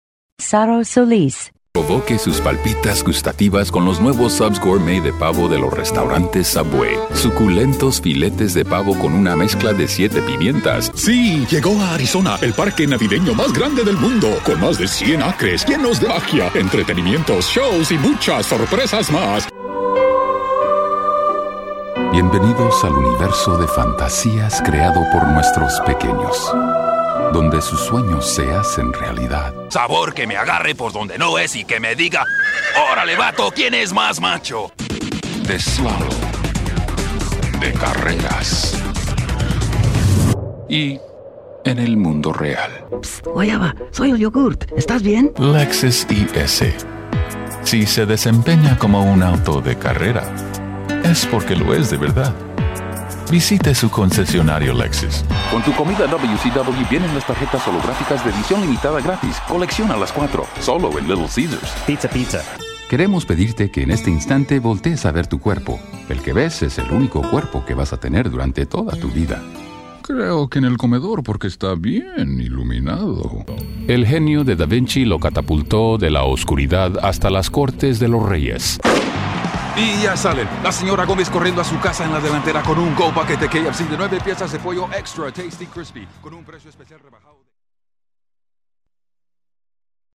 foreign language : spanish